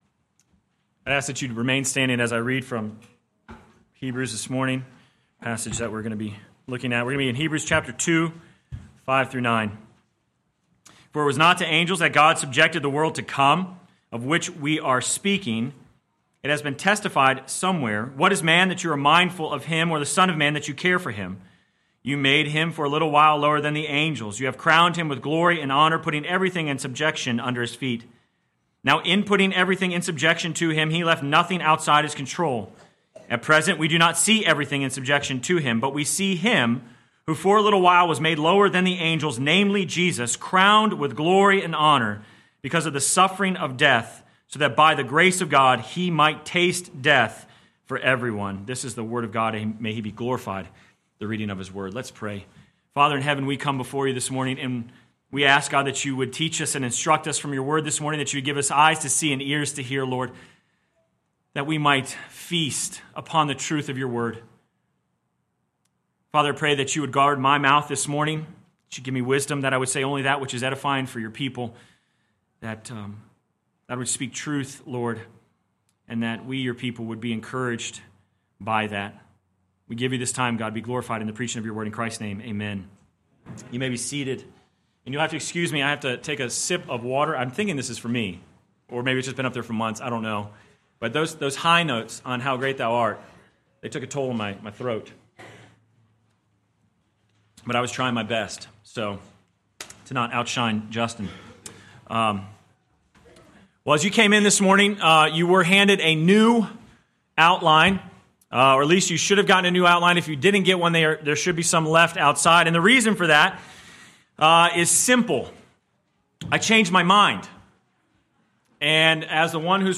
Sermon Text: Hebrews 2:5-18 First Reading: Psalm 8 Second Reading: Philippians 2:1-11